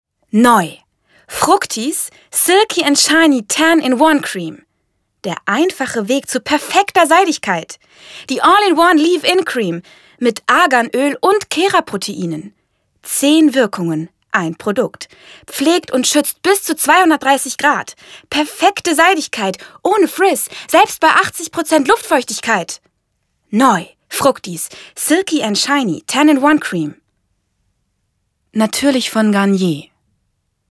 Sprechprobe: Werbung (Muttersprache):